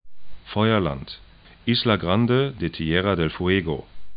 'fɔyɐlant